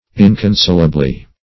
-- In`con*sol"a*ble*ness, n. -- In`con*sol"a*bly, adv.